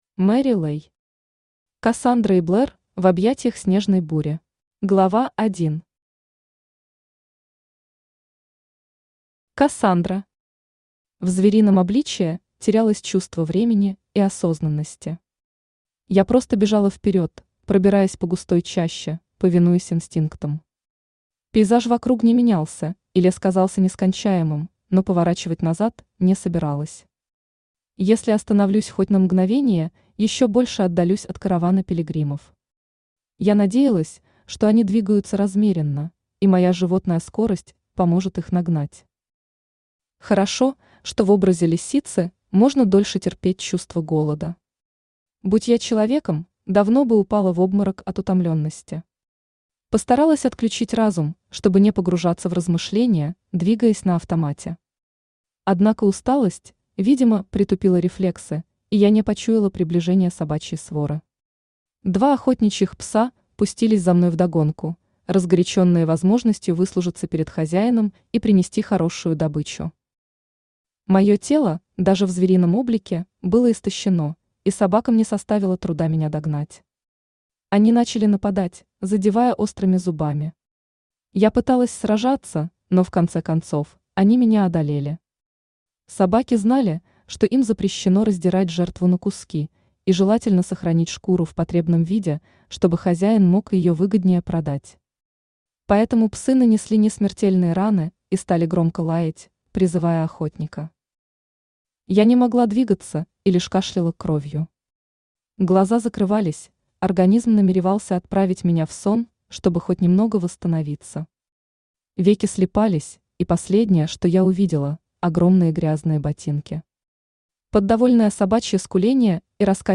Аудиокнига Кассандра и Блэр в объятиях снежной бури | Библиотека аудиокниг
Aудиокнига Кассандра и Блэр в объятиях снежной бури Автор Мэри Лэй Читает аудиокнигу Авточтец ЛитРес.